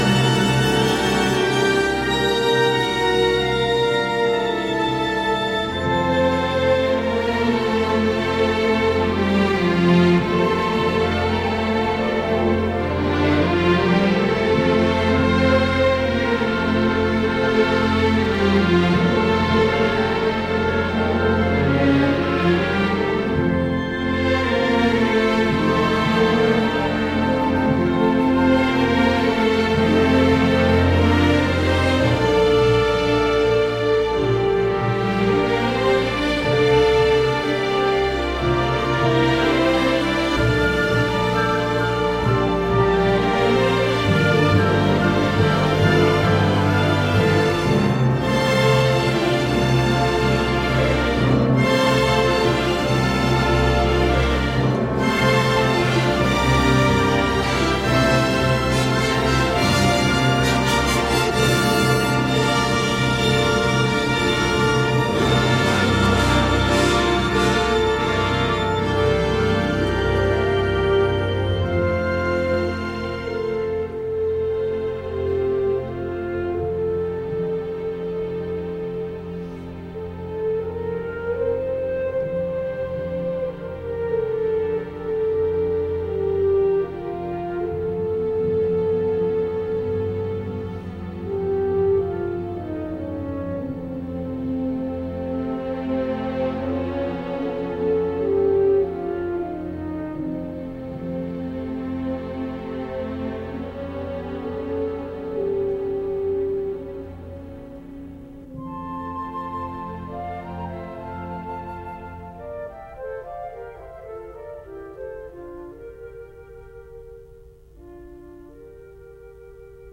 L'enregistrement démarre 1 mn avant l'heure prévue du début de l'émission Date Programme
Un programme musical proposé et animé